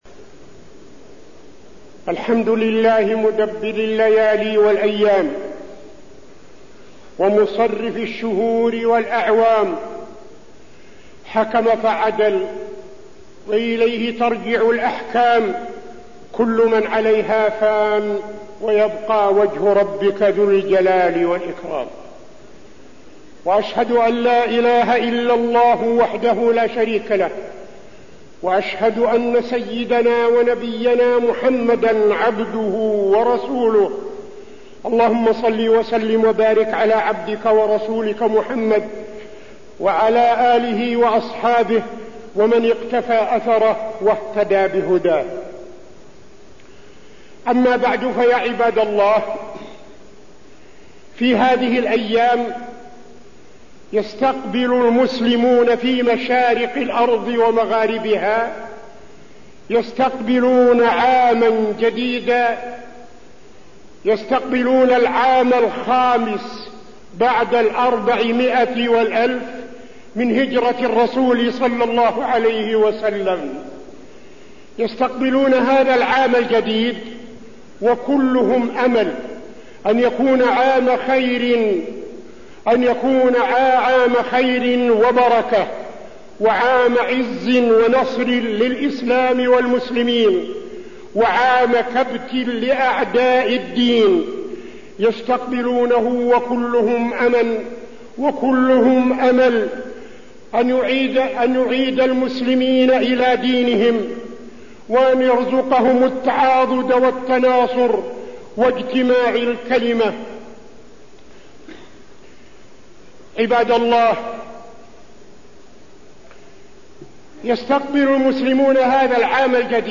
تاريخ النشر ٢٦ ذو الحجة ١٤٠٤ هـ المكان: المسجد النبوي الشيخ: فضيلة الشيخ عبدالعزيز بن صالح فضيلة الشيخ عبدالعزيز بن صالح استقبال عام جديد